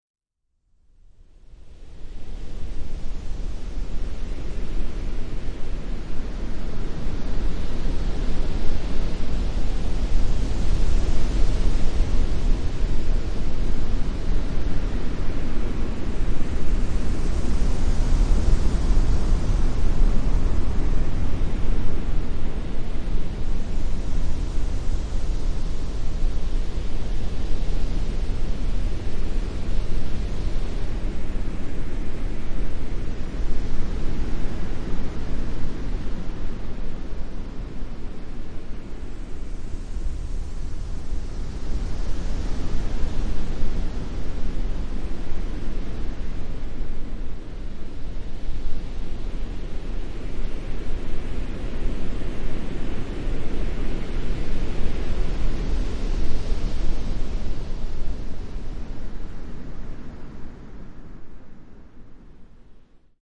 Howling Wind.mp3